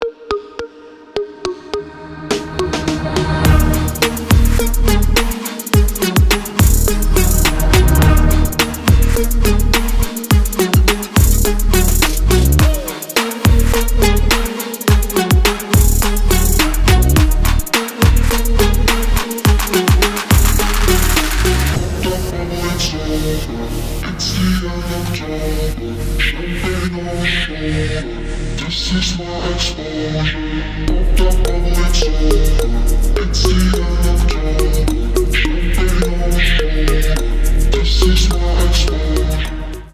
• Качество: 320 kbps, Stereo
Электроника
клубные